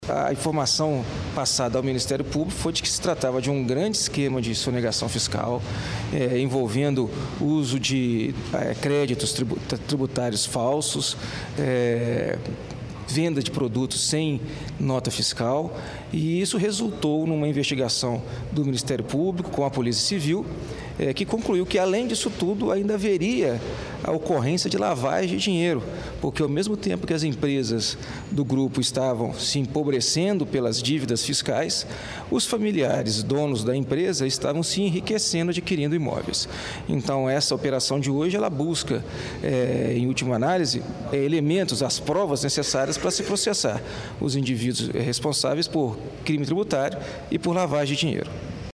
Segundo o promotor de Justiça Fábio Reis Nazareth, da Promotoria de Defesa da Ordem Econômica e Tributária, as investigações começaram em 2007.